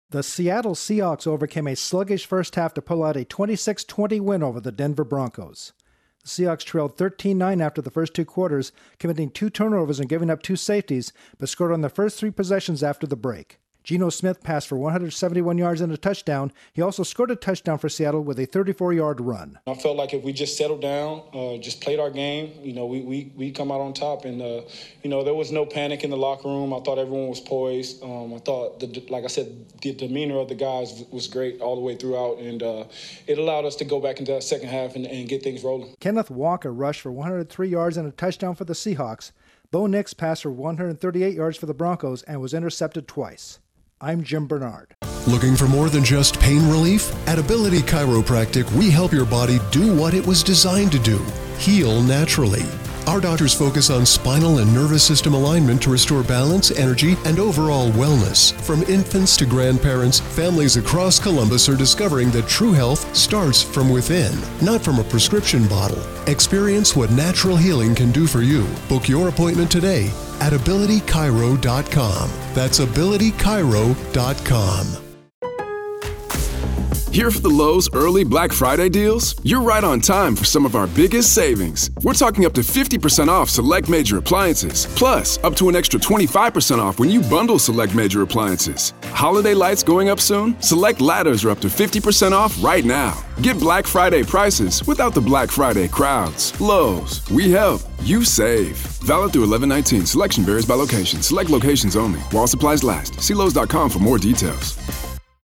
The Seahawks rally for a win against the Broncos. Correspondent